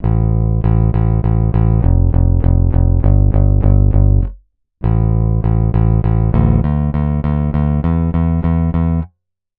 slide bass.wav